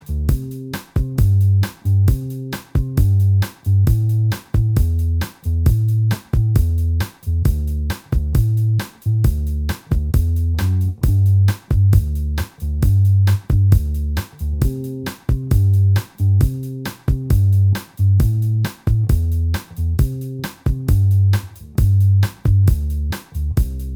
Minus Electric Guitar Soft Rock 6:16 Buy £1.50